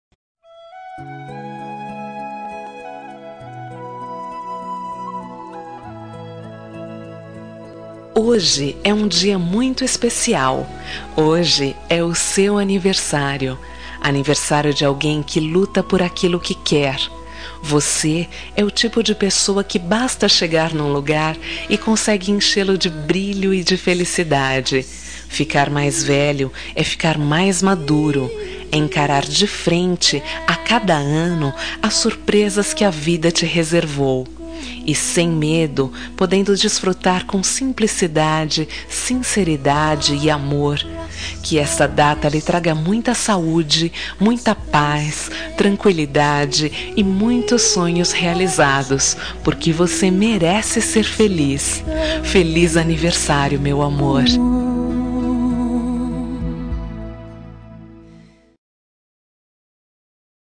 Telemensagem Aniversário de Paquera -Voz Feminina – Cód: 1236 – Linda